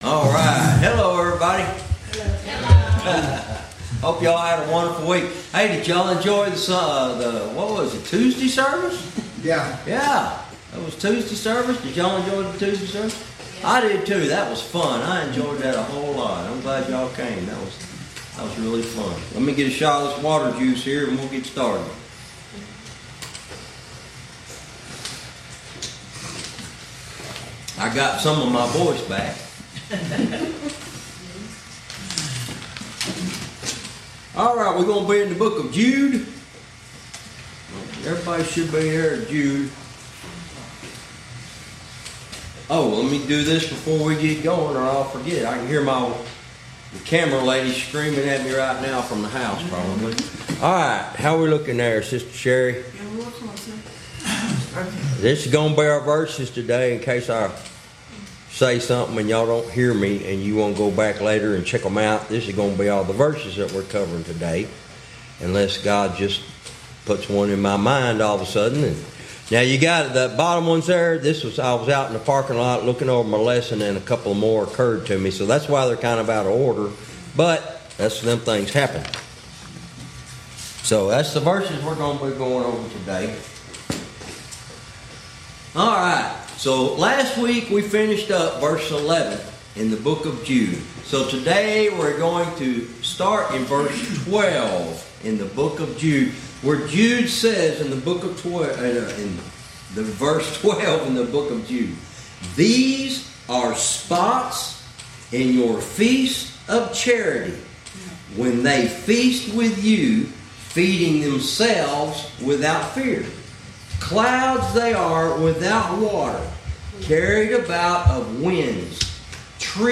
Verse by verse teaching - Lesson 46 Verse 12